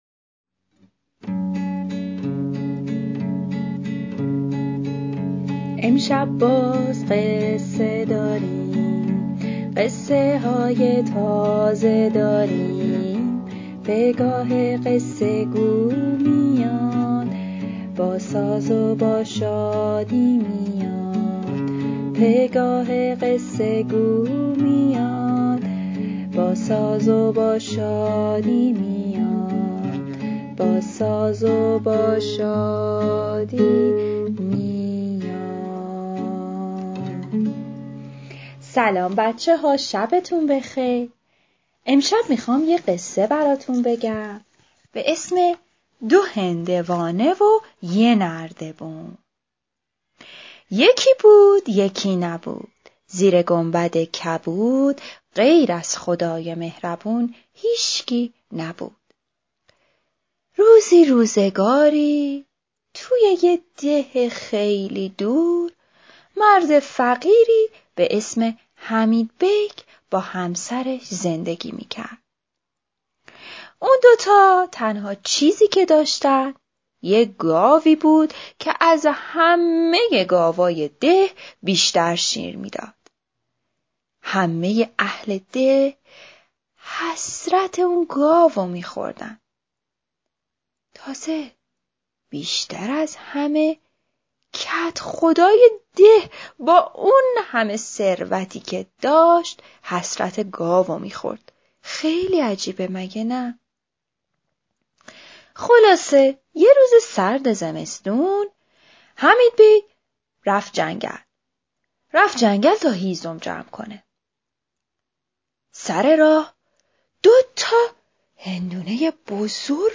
قصه کودکانه صوتی دو هندوانه و یک گاو
قصه-کودکانه-صوتی-دو-هندوانه.mp3